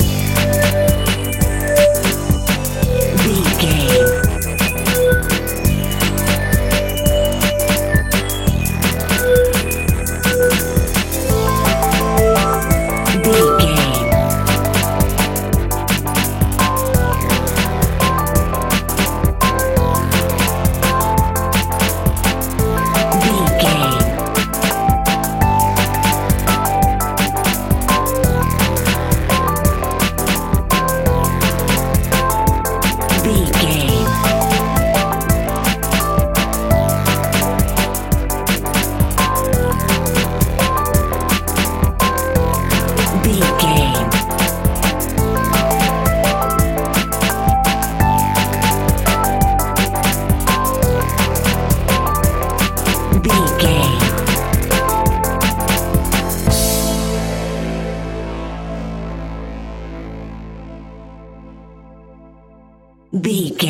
Aeolian/Minor
Fast
futuristic
hypnotic
industrial
dreamy
frantic
aggressive
synthesiser
drums
sub bass
synth leads